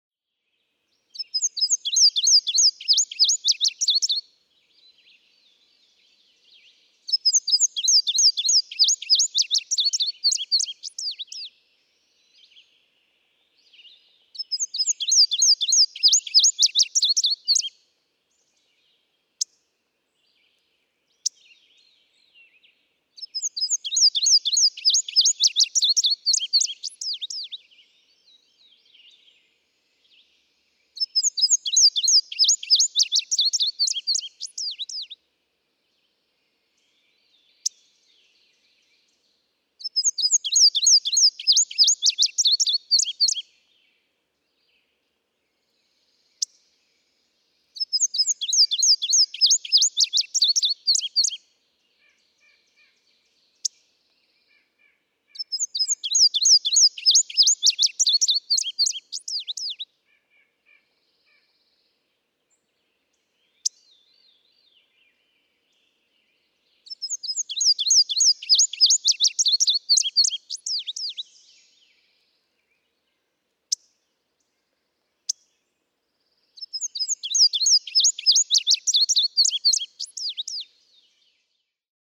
Indigo bunting
♫173. Adult song. May 17, 2006. Quabbin Park, Ware, Massachusetts. (1:21)
173_Indigo_Bunting.mp3